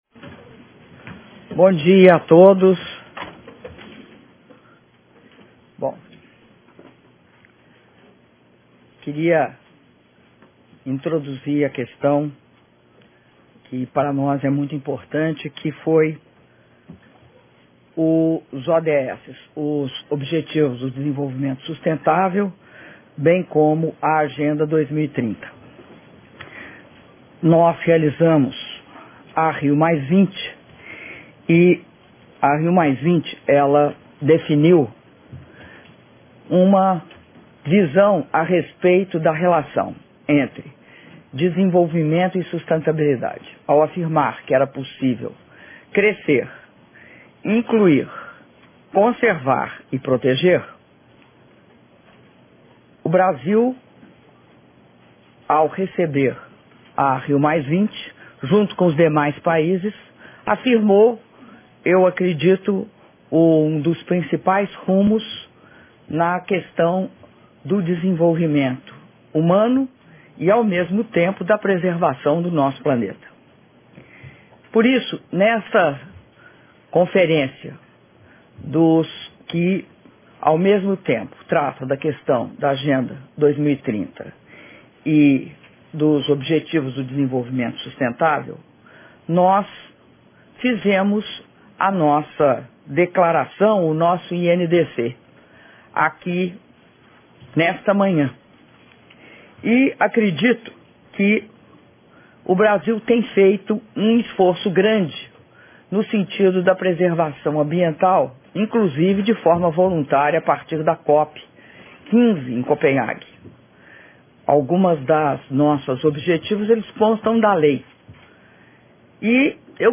Áudio da entrevista coletiva concedida pela Presidenta da República, Dilma Rousseff, nas Nações Unidas - Nova Iorque/EUA (48min33s)